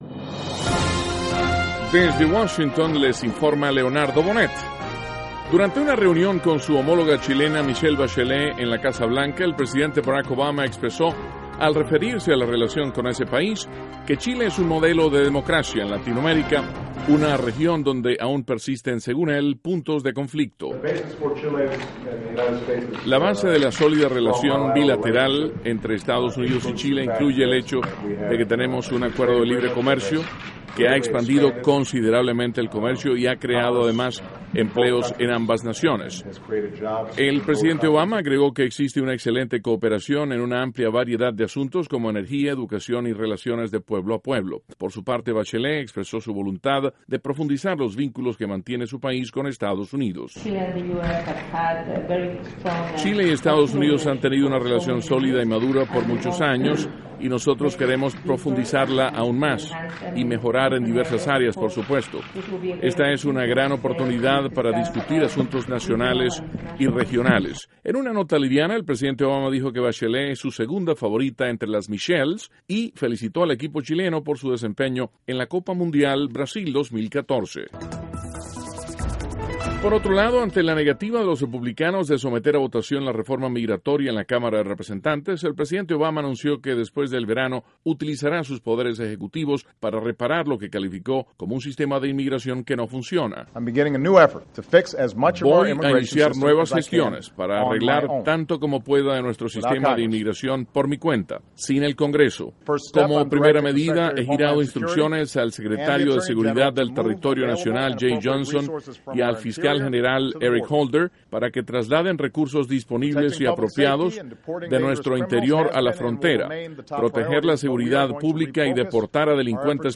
NOTICIAS - LUNES, 30 DE JUNIO, 2014
Duración: 3:00 Contenido: 1.- El presidente Obama recibe en la Casa Blanca a la mandataria chilena, Michelle Bachelet. (Sonidos Obama y Bachelet) 2.- El presidente Obama anuncia adopción de medidas migratorias sin el apoyo del Congreso. (Sonido Obama) 3.- Los nuevos reyes de España visitan al Papa Francisco, en el Vaticano.